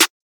Everything Snare.wav